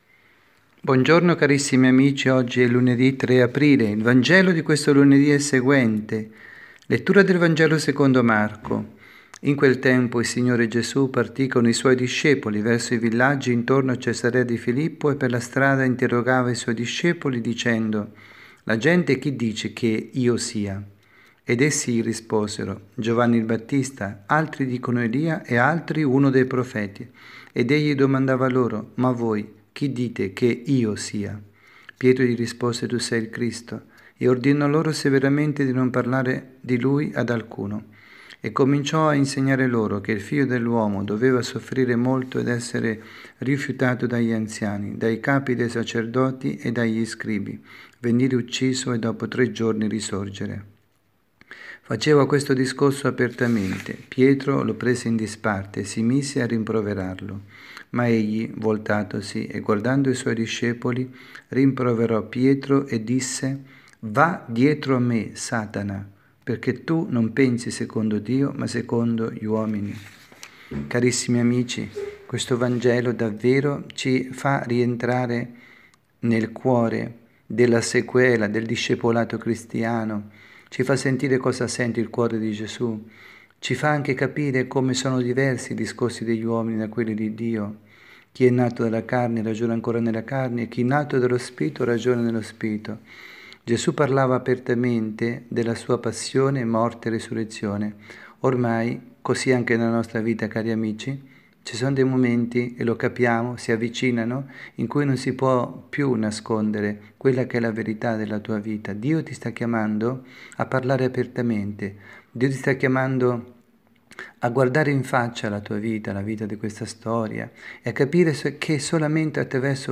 Catechesi
dalla Parrocchia S. Rita, Milano